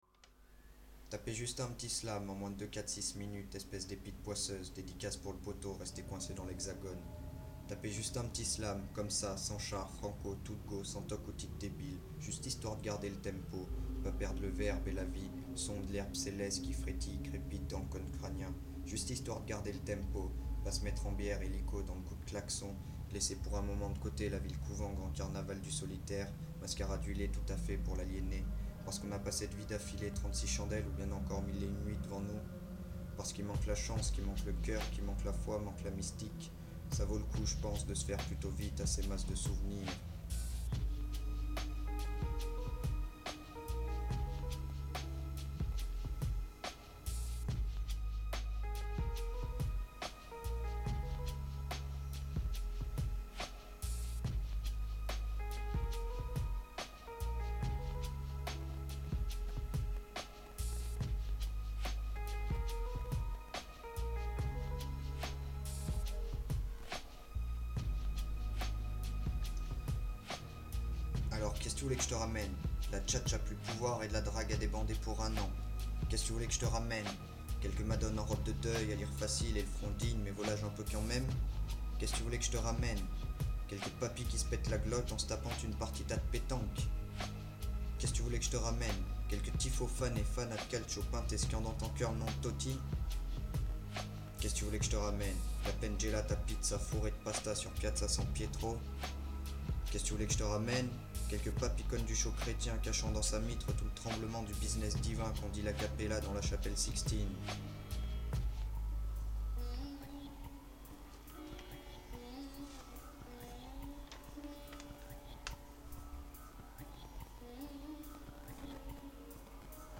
Roma's shadow Un slam qui en jette.